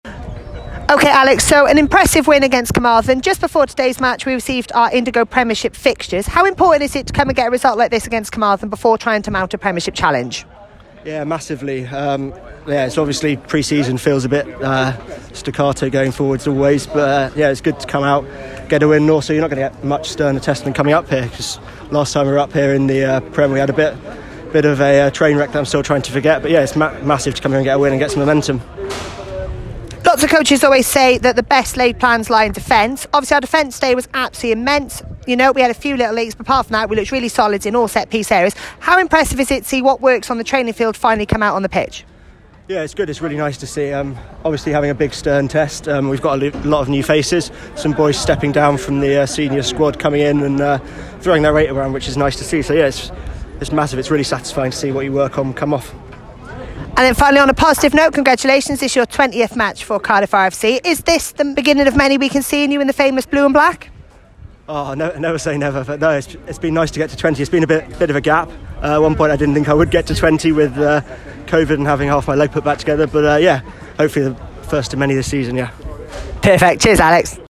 Post Match Interviews